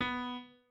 piano3_6.ogg